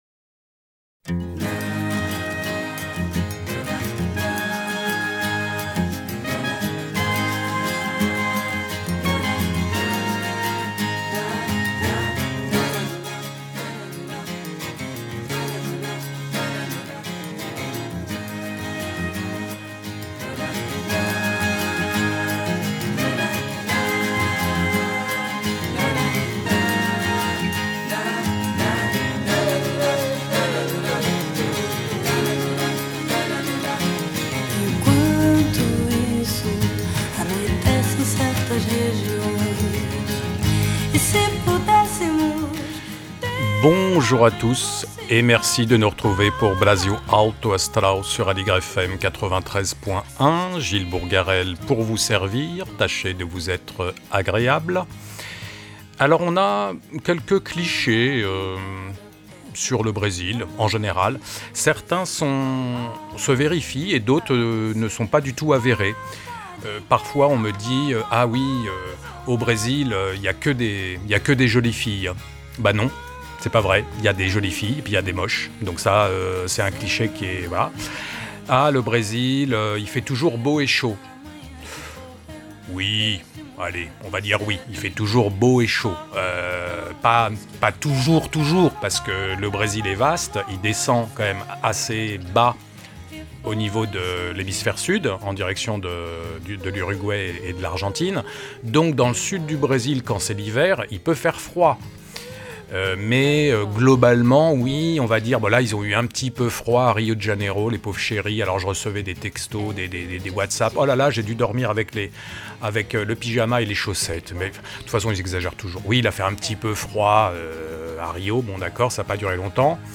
Commençons par quelques chichés, puis éprouvons le bonheur de chanter ensemble.